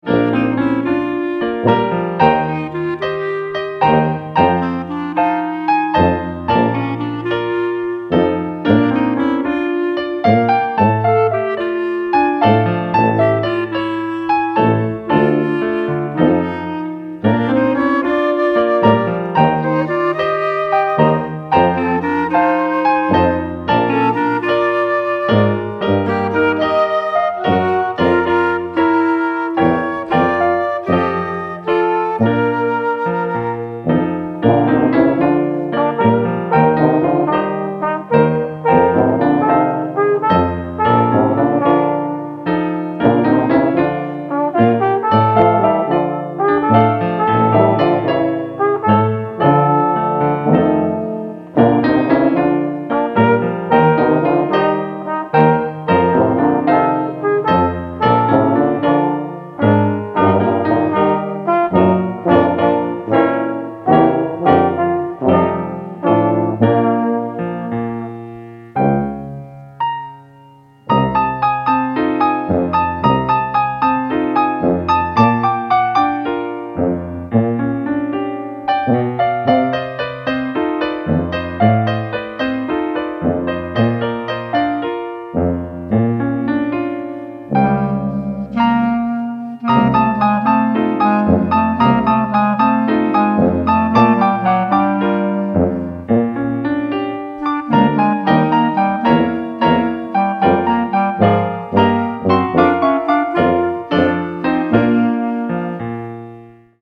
Accueil Ragtimes, valses & bostons Solace Solace Scott Joplin Écoutez Solace de Scott Joplin interpreté par la Fanfare Octave Callot Téléchargez le morceau ← Calliope Rag ↑ Ragtimes, valses & bostons Smiles and chuckles →